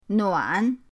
nuan3.mp3